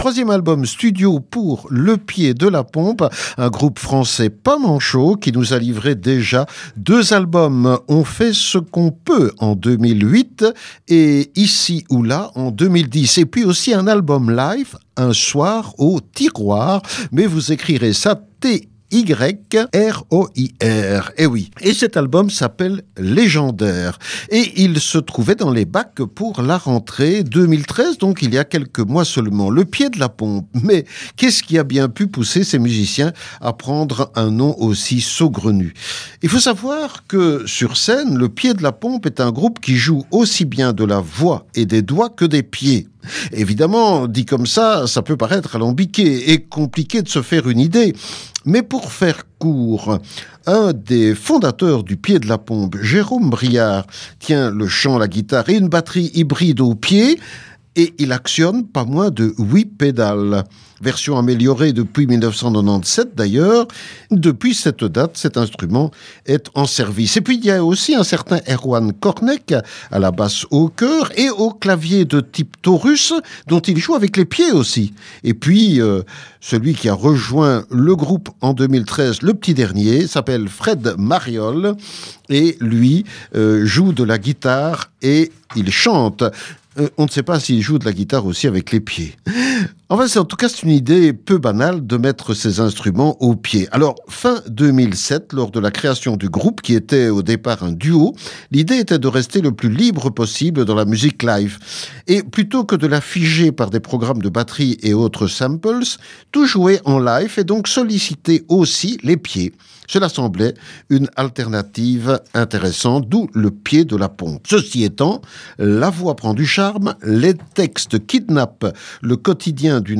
la basse, choeurs et claviers de «Type Taurus» aux pieds
choeurs et Theremin.